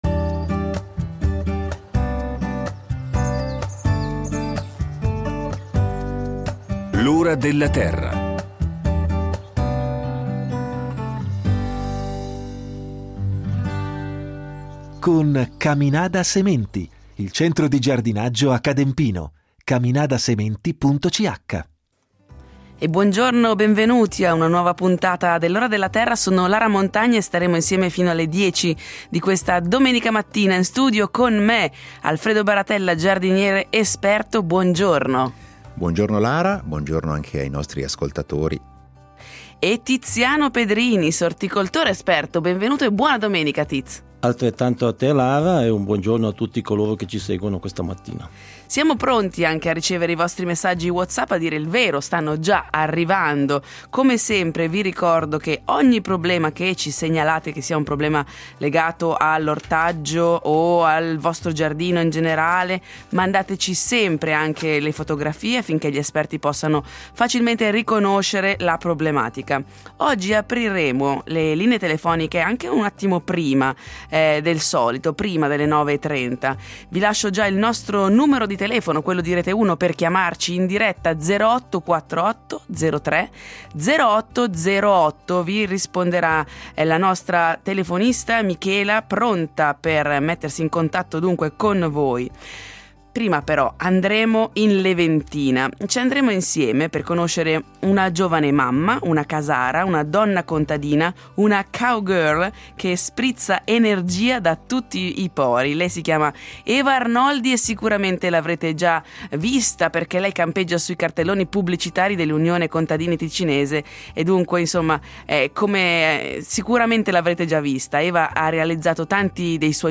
Lasciamo che sia proprio lei, durante un trekking alpino, a raccontarci del suo sogno, divenuto realtà. Nella seconda parte del programma in studio gli esperti risponderanno alle domande del pubblico da casa su piante da frutta, orto e giardinaggio.